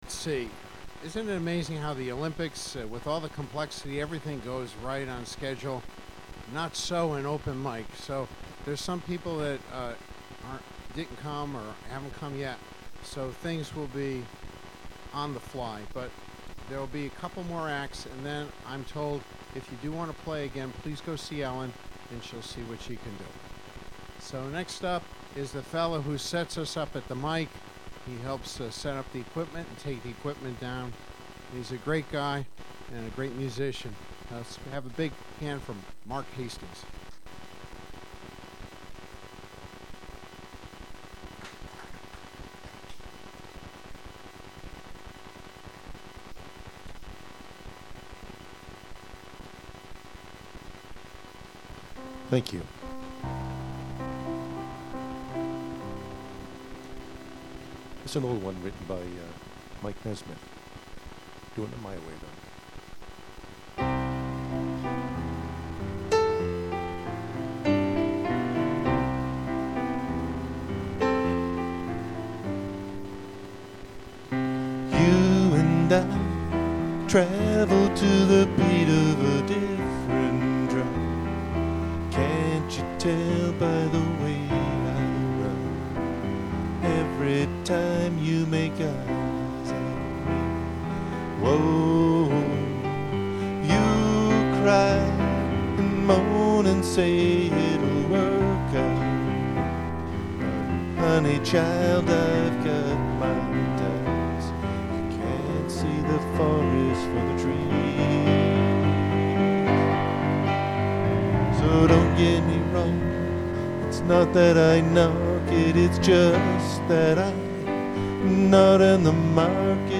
Files with a title starting raw or Untitled have only been track-level volume adjusted and are not joined, clipped, equalized nor edited.
raw Nourish Restaurant Open Mike, 8/7/12
Apologies for the hum/noise in the recordings.